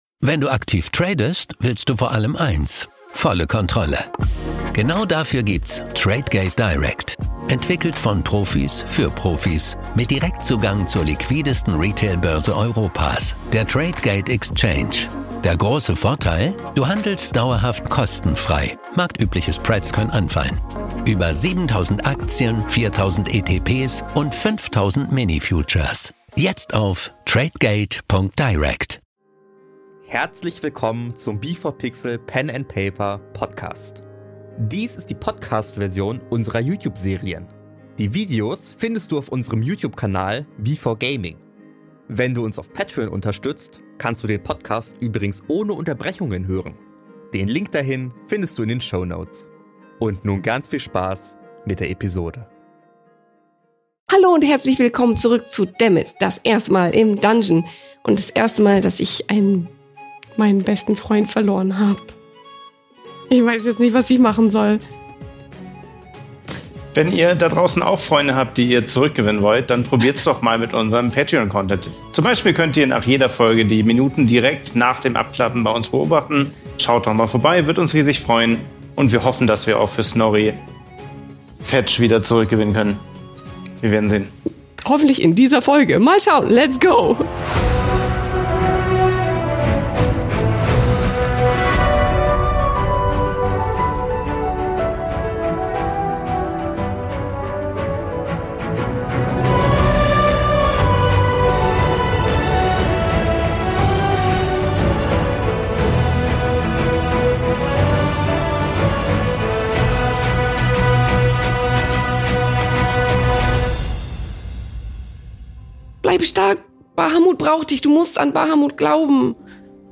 Dies hier ist die Podcast-Version mit Unterbrechungen.